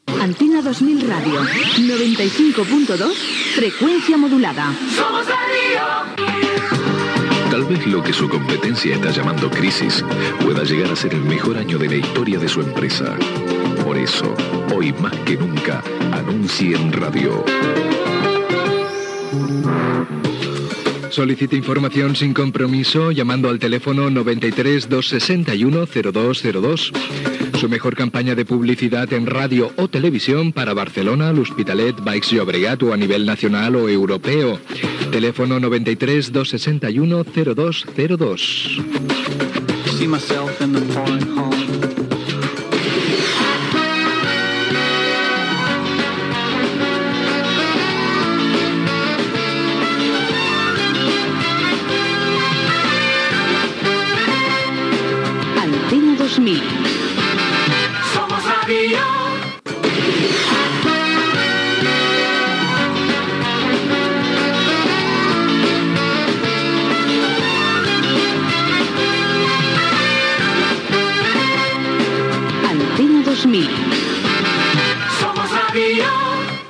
Indicatiu de l'emissora i anunci sobre com posar publicitat a l'emissora, indicatiu
FM